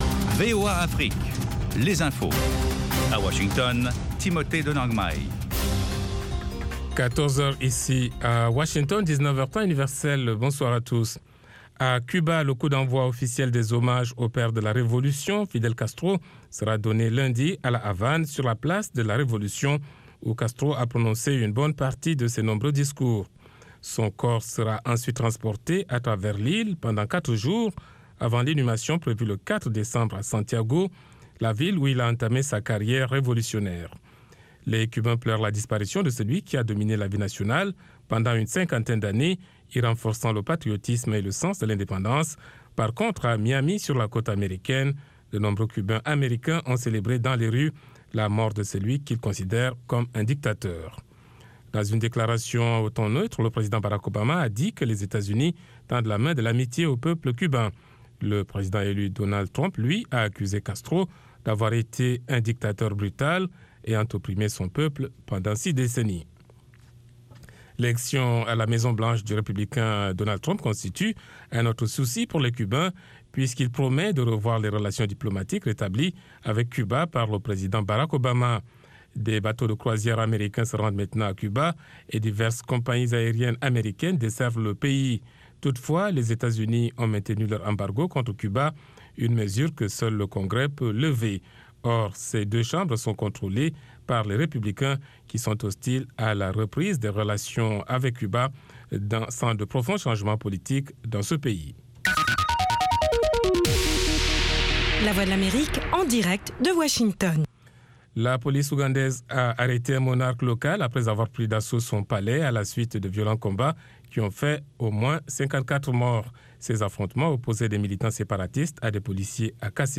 Découvrez aussi les sections rythmiques des grands studios où ils ont enregistré leurs plus gros hits : Memphis, Muscle Shoals, Motown, et Philadelphie.